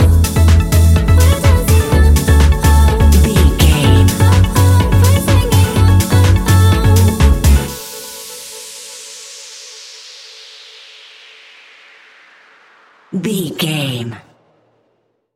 Ionian/Major
F♯
house
electro dance
synths
techno
trance
instrumentals